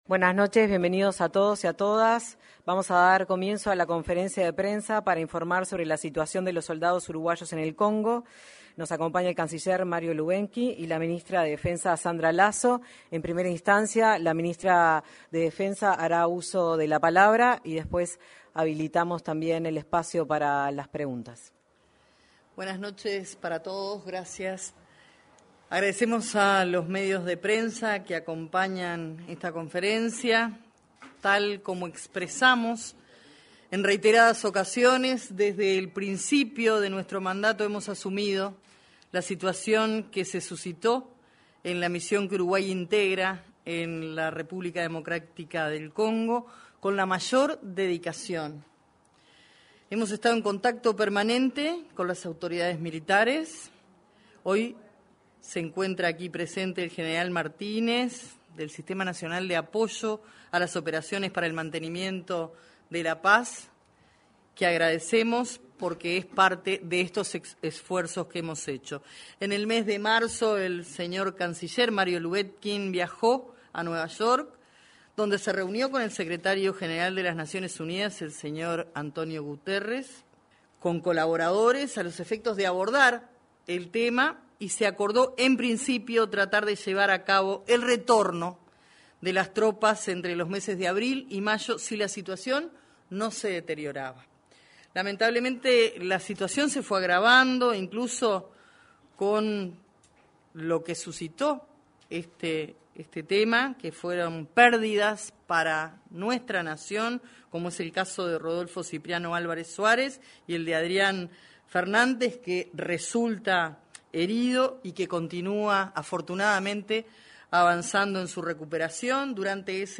Conferencia de prensa de autoridades del Gobierno en Torre Ejecutiva
Los ministros de Relaciones Exteriores, Mario Lubetkin, y Defensa Nacional, Sandra Lazo, se expresaron en una conferencia de prensa, este viernes 20